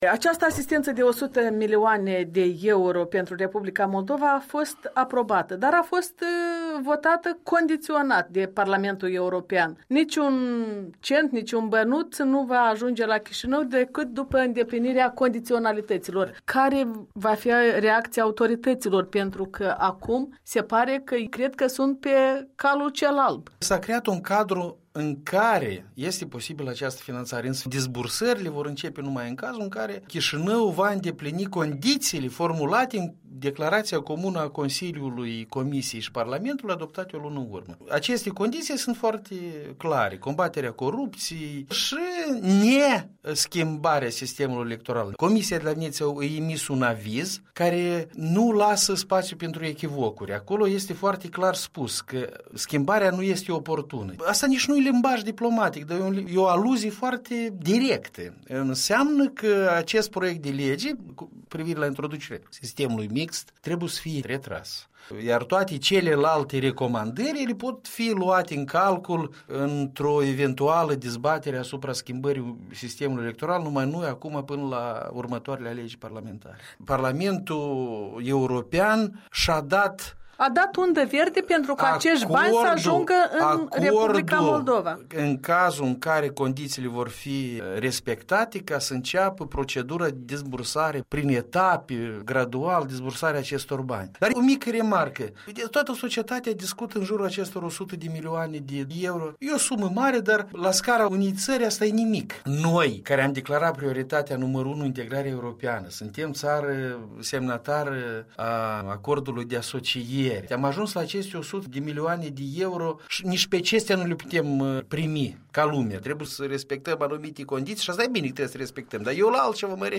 Interviu cu fostul ambasador al R. Moldova la ONU și la Consiliul Europei.